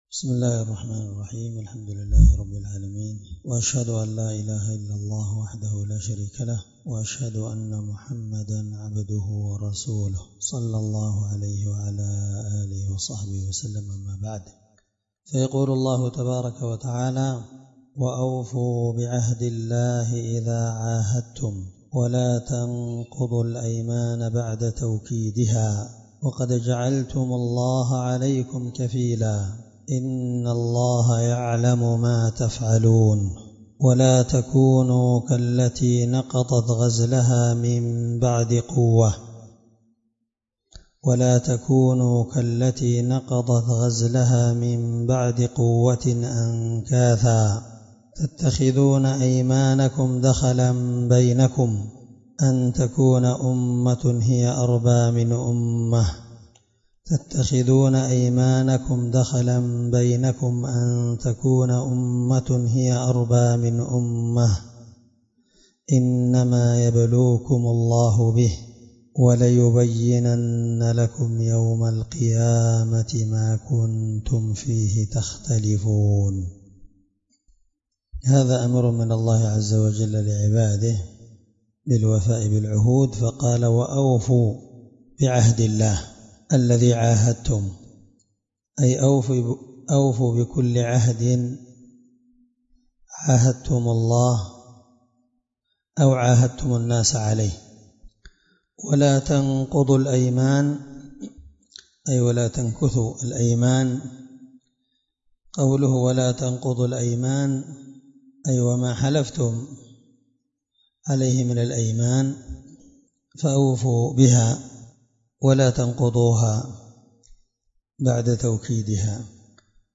الدرس30 تفسير آية (91) من سورة النحل